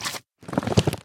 MinecraftConsoles / Minecraft.Client / Windows64Media / Sound / Minecraft / mob / magmacube / jump4.ogg
jump4.ogg